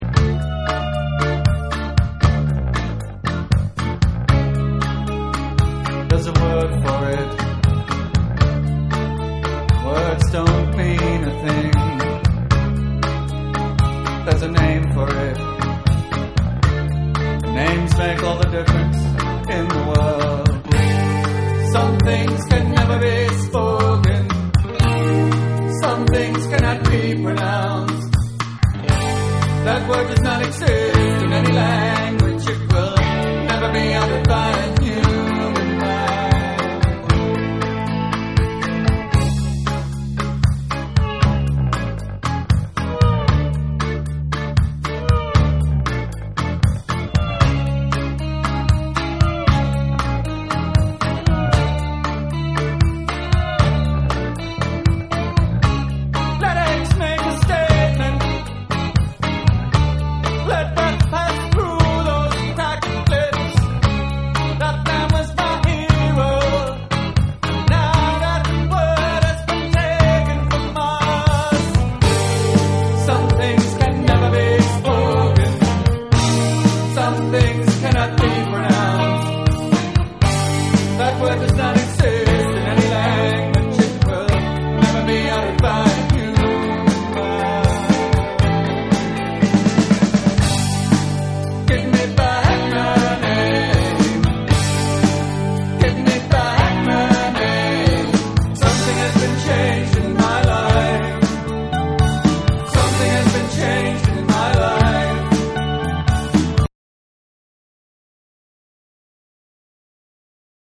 NYニューウェイヴを代表するバンド
エクスペリメンタルでアフロ・テイストなエレクトリック・ファンク・ナンバー
NEW WAVE & ROCK